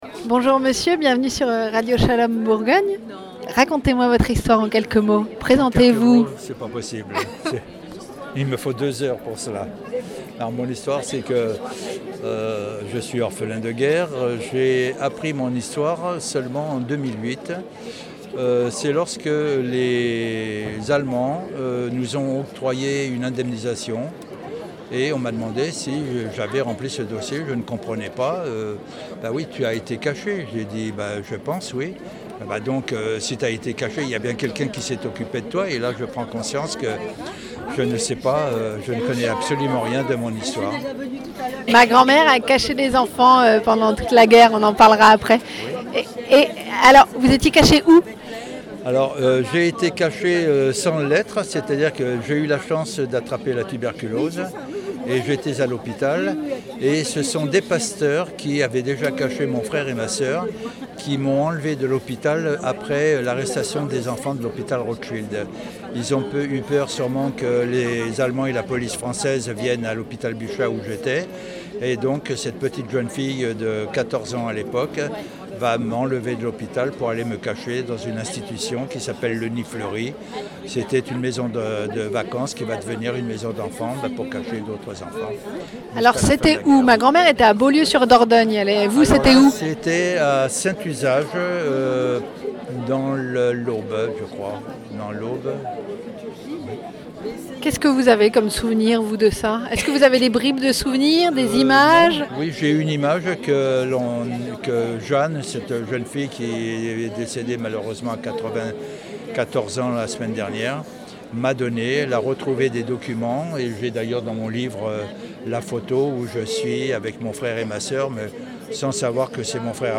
Mémorial de la Shoah-Entrevue
MEMORIAL DE LA SHOAH A PARIS 2° FORUM GENERATIONS DE LA SHOAH 2-4 Juillet 2022